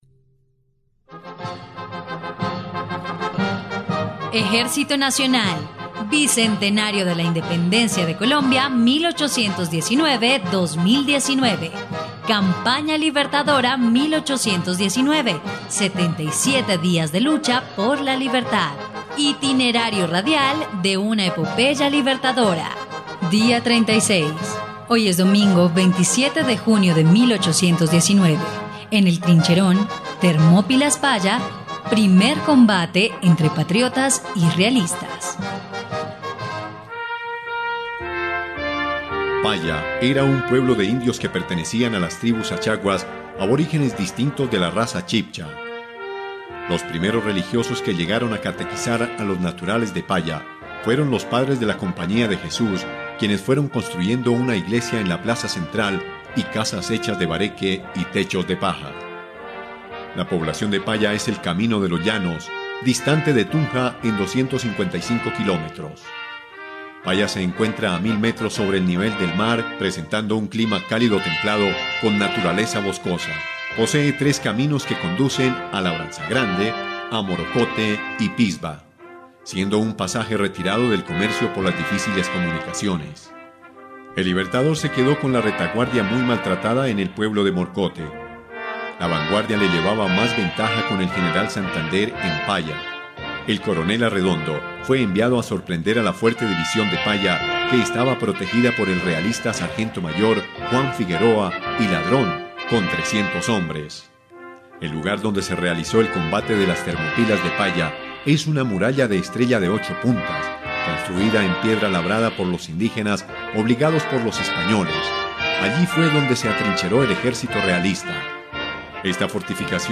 dia_36_radionovela_campana_libertadora.mp3